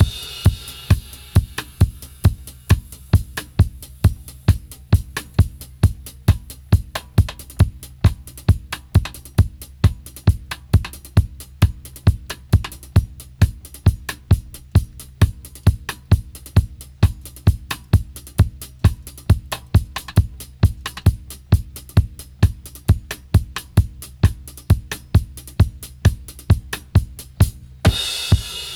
134-DRY-02.wav